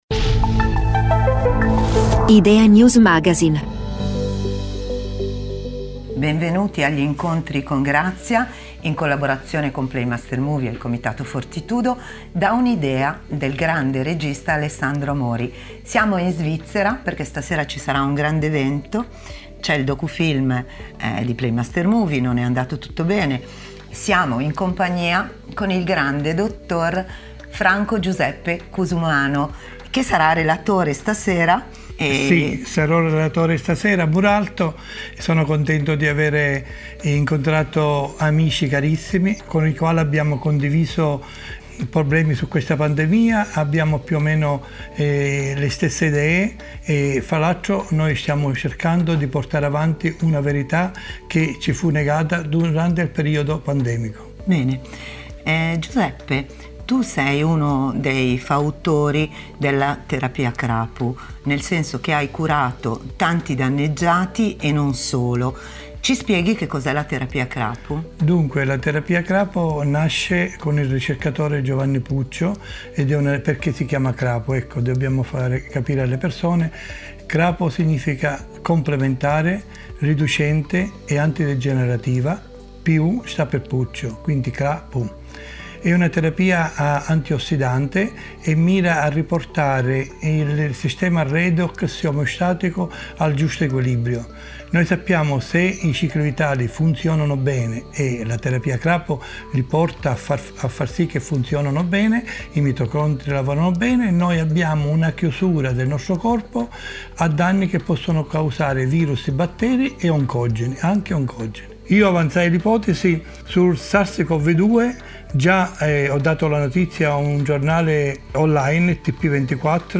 Contesto: Evento in Svizzera